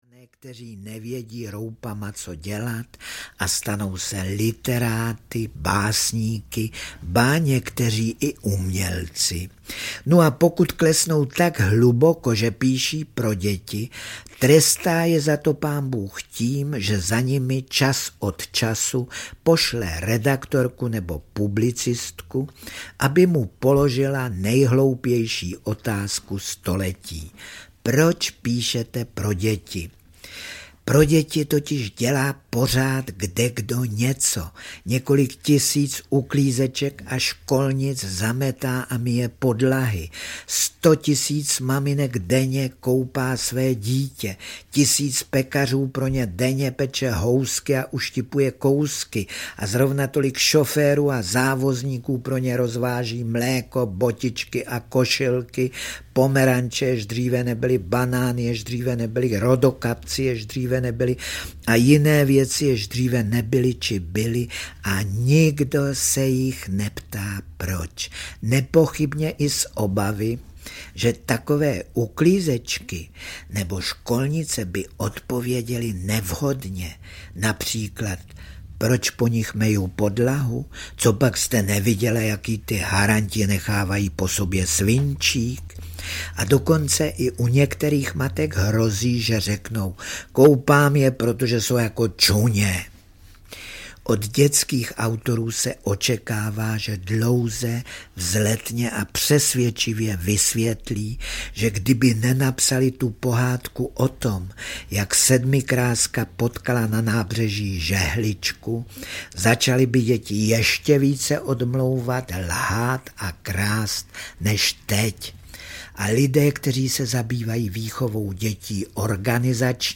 Dobrou a ještě lepší neděli audiokniha